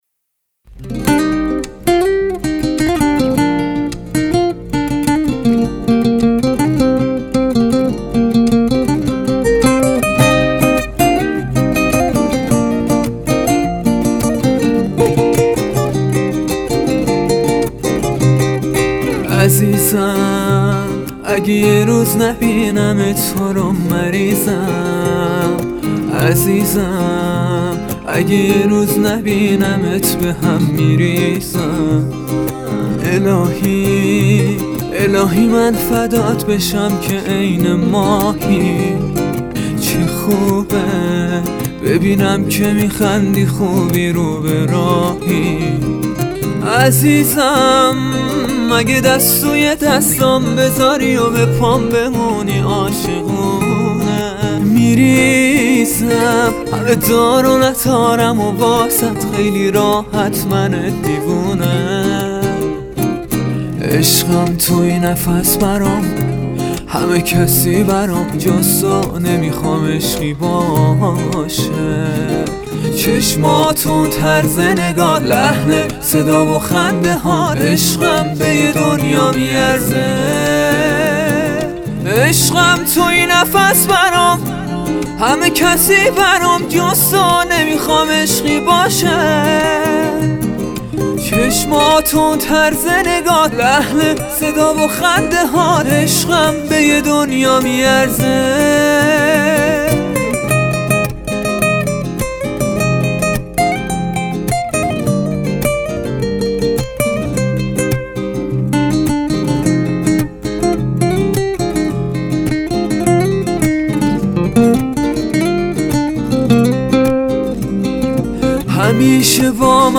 آهنگ کردی